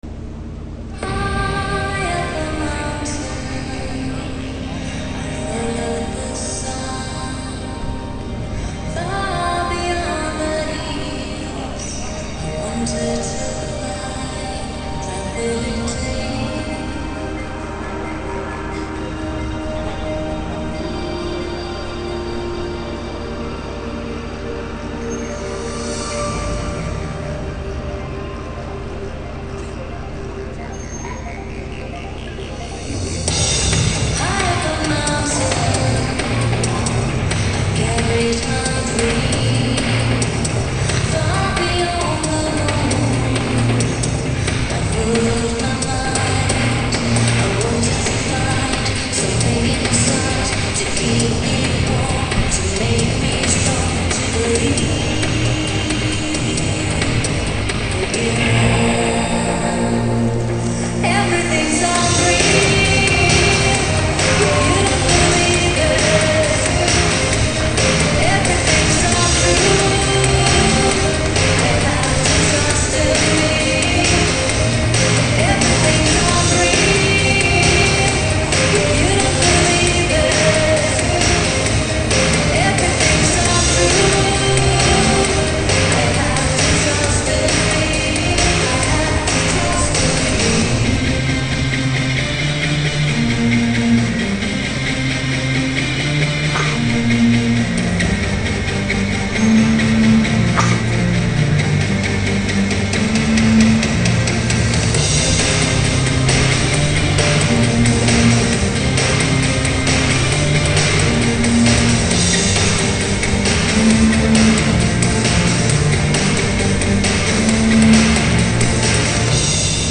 mp3_high_up_a_mountain_live.MP3